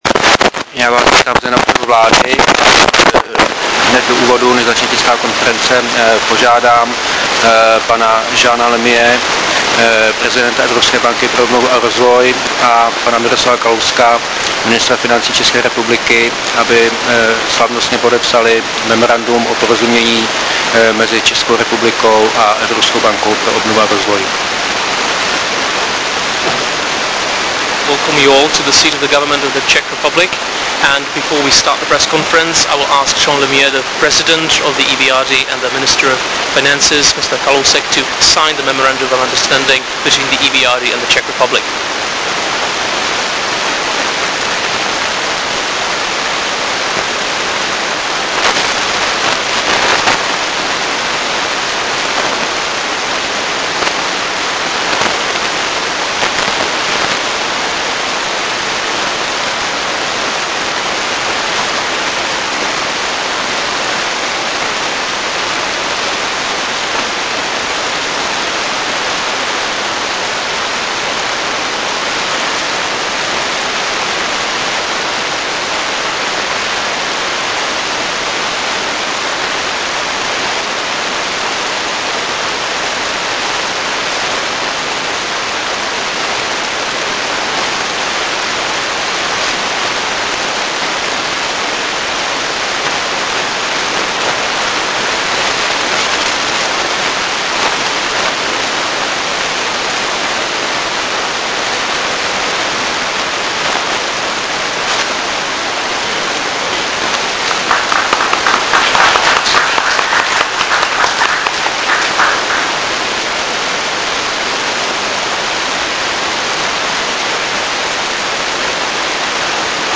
Tisková konference předsedy vlády ČR Mirka Topolánka a prezidenta Evropské banky pro obnovu a rozvoj Jeana Lemierra dne 25.10.2007